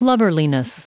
Prononciation du mot lubberliness en anglais (fichier audio)
Prononciation du mot : lubberliness